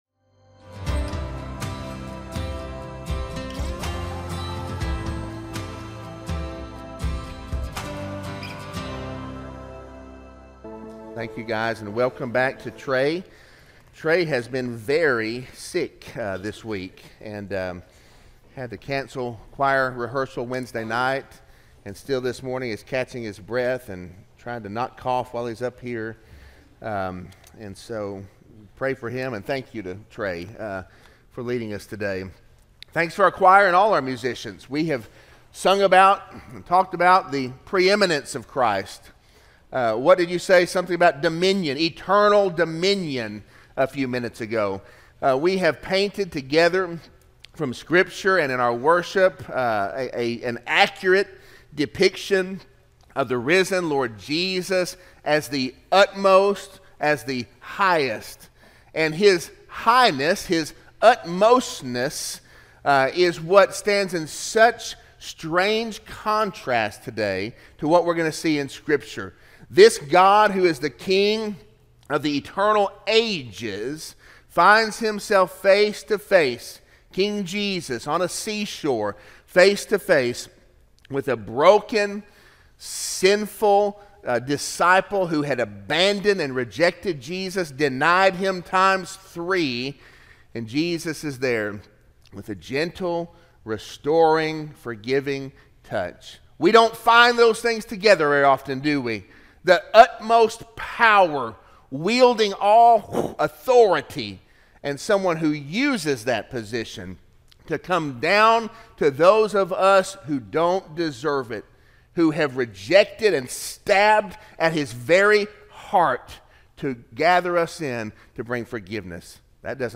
Sermon-4-14-24-audio-from-video.mp3